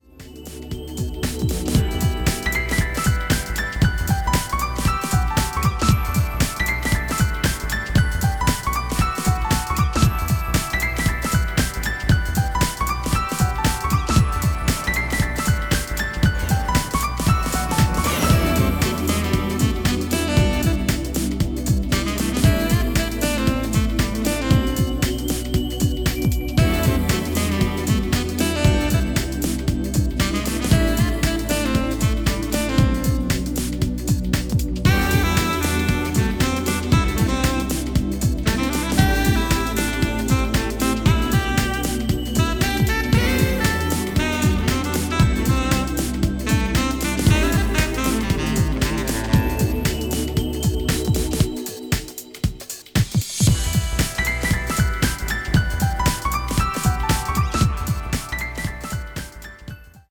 当社にて、BGM用に製作している楽曲のいくつかを、サンプルとしてご紹介致します。
各曲、ステレオと 5.1ch Surround の二つのフォーマットをご用意致しました（楽曲の内容は同一です）。